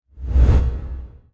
Standard Swoosh Sound Effect Free Download
Standard Swoosh